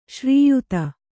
♪ śrīyuta